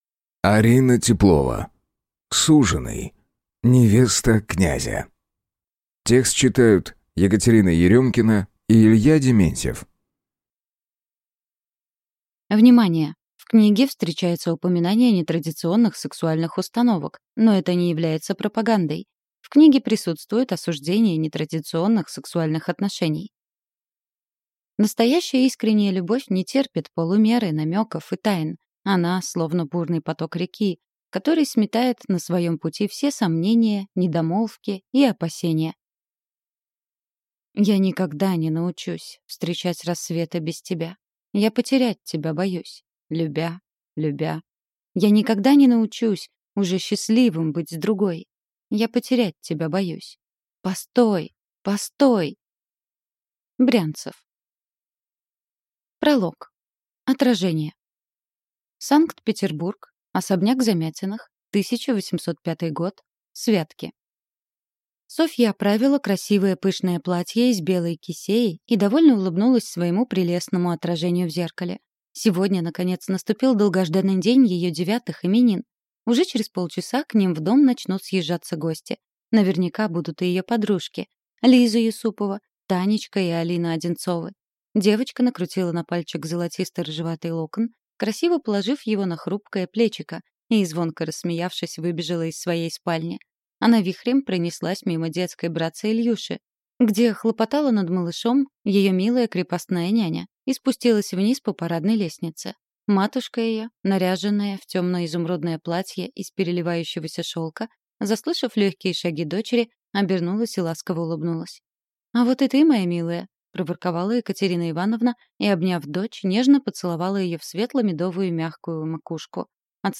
Аудиокнига Суженый. Невеста князя | Библиотека аудиокниг